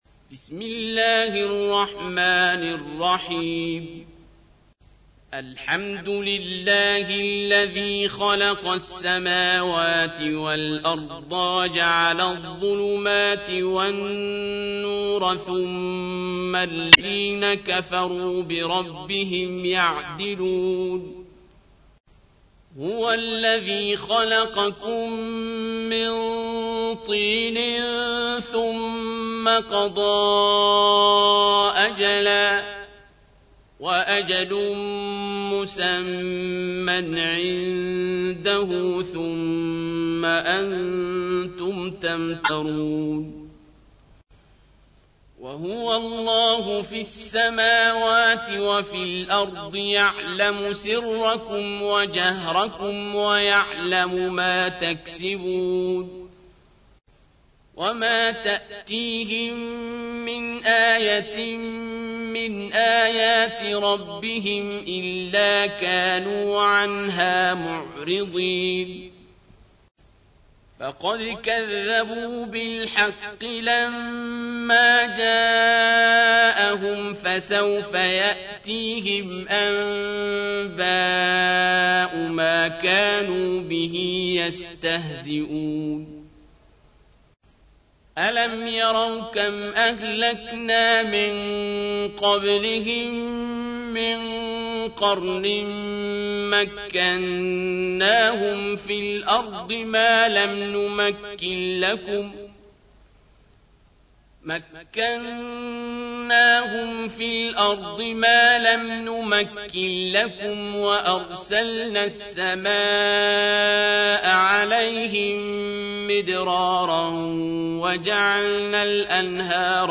تحميل : 6. سورة الأنعام / القارئ عبد الباسط عبد الصمد / القرآن الكريم / موقع يا حسين